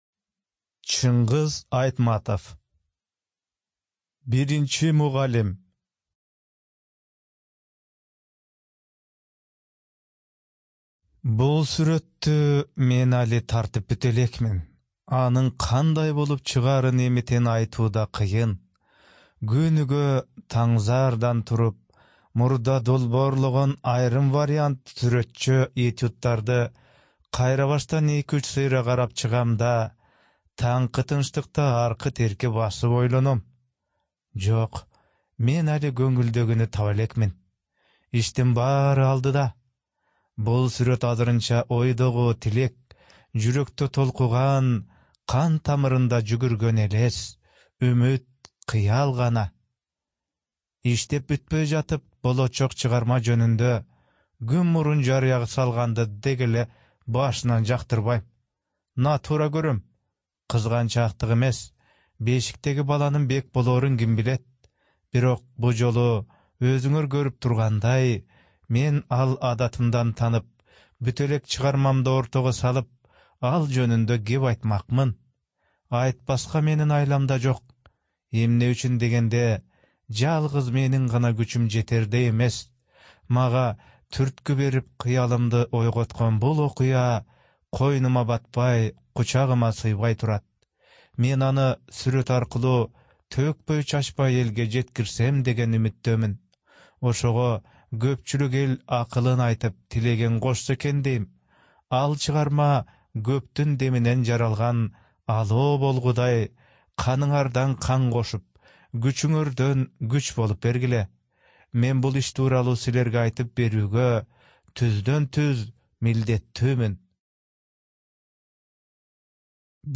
Студия звукозаписиКыргызская Республиканская специализированная библиотека для слепых и глухих